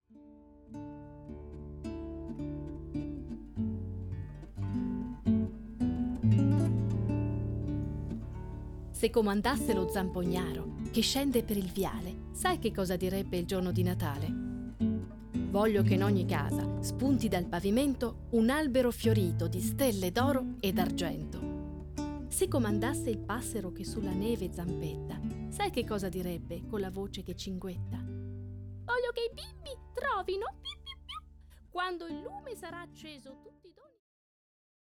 Suoni e parole di natale: I brani più famosi della tradizione natalizia arrangiati per ensemble di flauti di bambù, alternati a letture di estratti da racconti di vari autori e generi sul Natale.